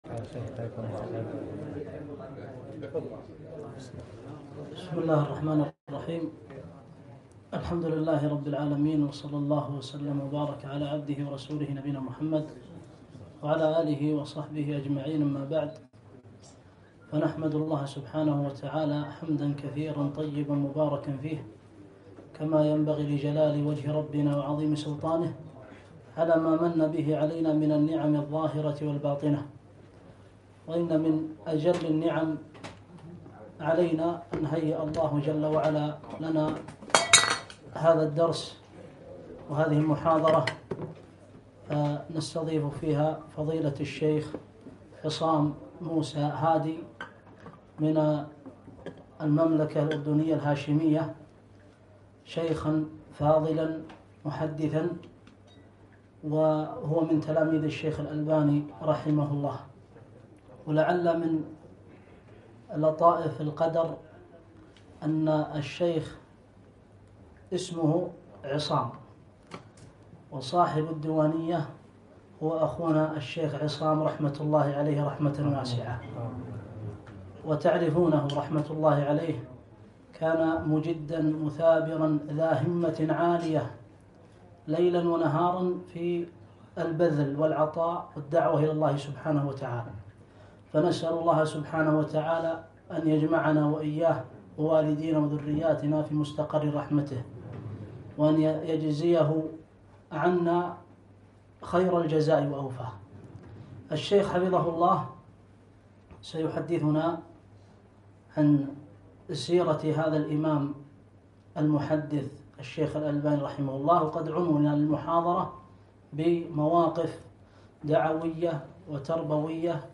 محاضرة - مواقف دعوية وتربوية من حياة العلامة الألباني